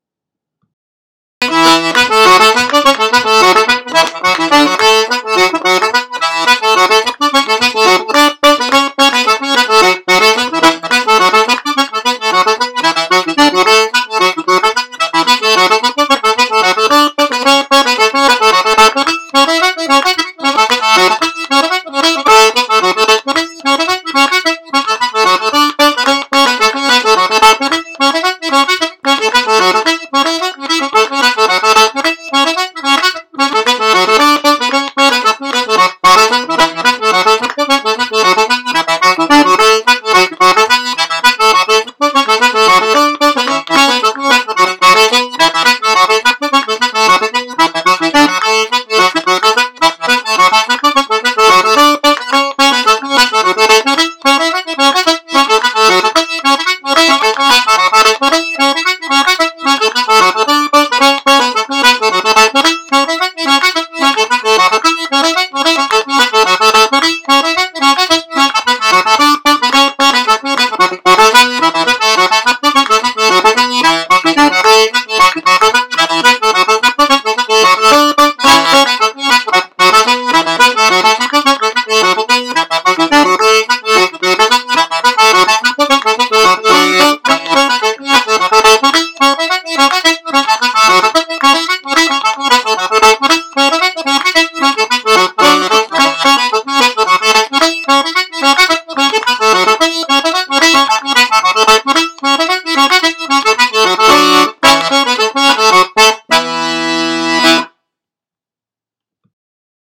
New Mown Meadows (110 bpm) – Sean-nós & Set Dance